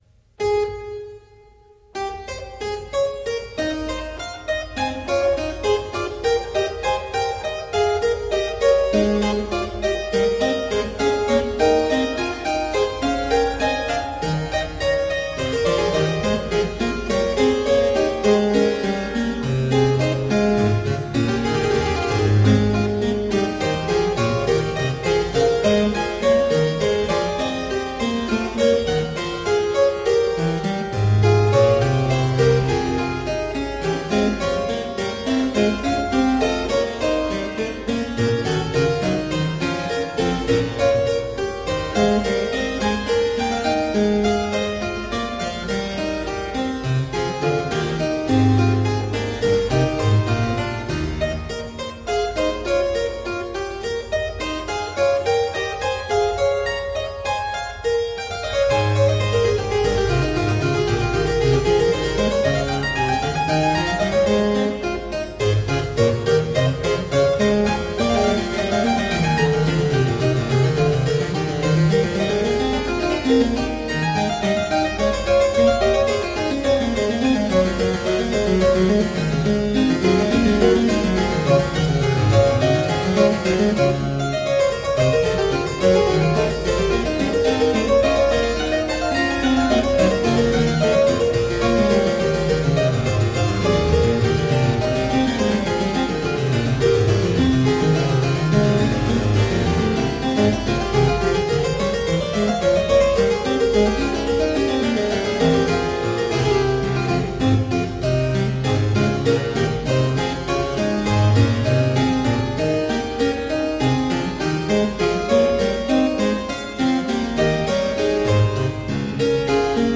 Solo harpsichord music
Tagged as: Classical, Baroque, Instrumental Classical
Harpsichord